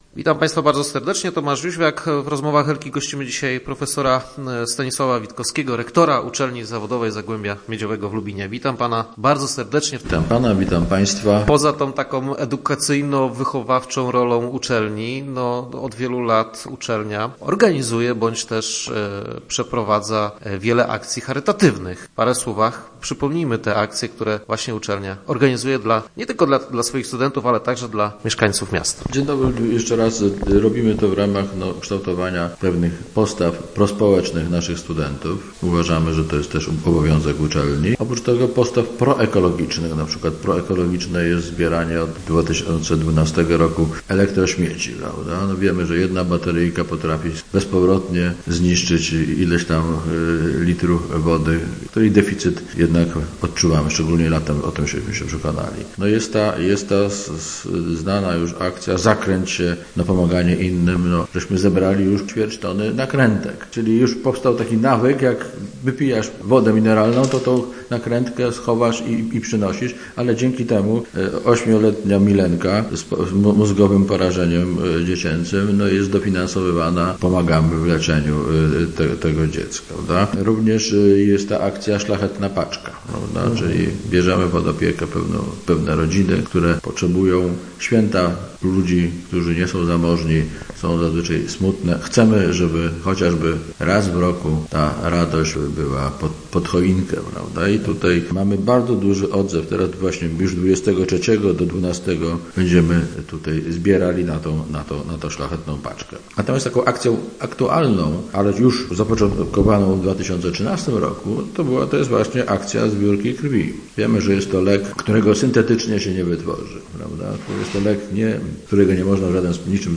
Start arrow Rozmowy Elki arrow Działamy też charytatywnie